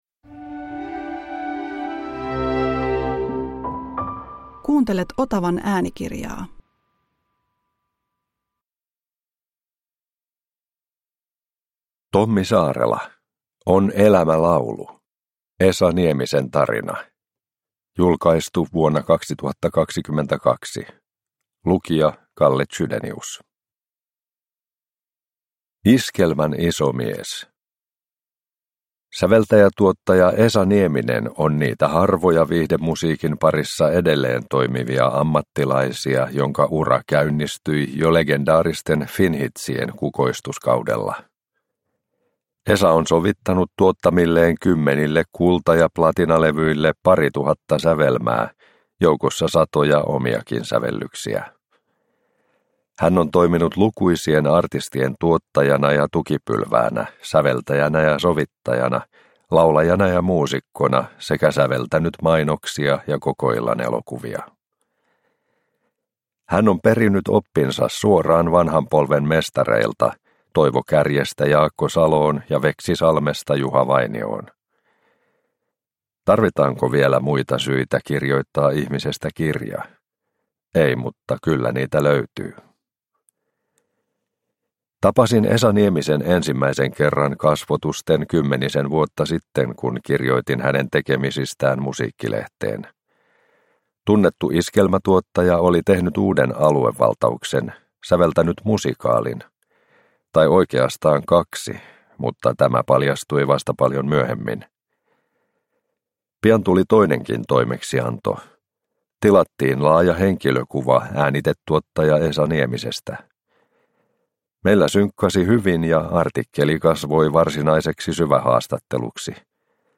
On elämä laulu – Ljudbok – Laddas ner